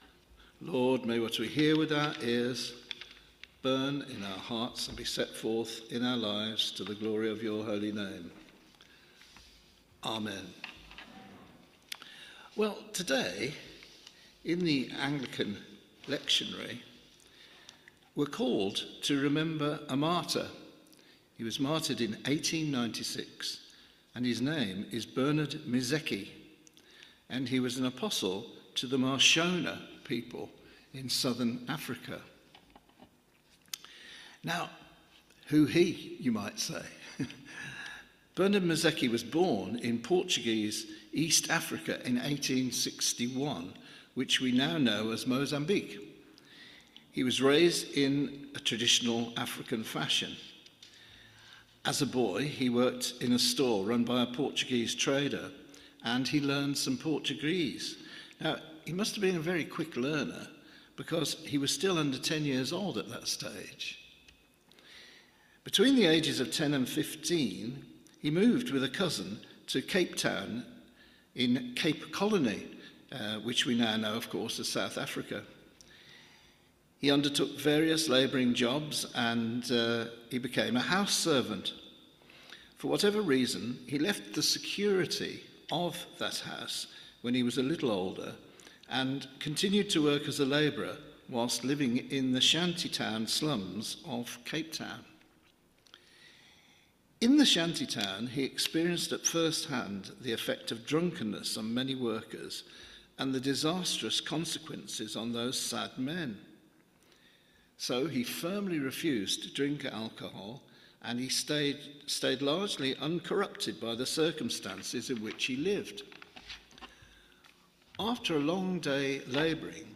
Media for Midweek Communion on Wed 18th Jun 2025 10:00 Speaker
Theme: Bernard Mizeki, martyr Sermon Search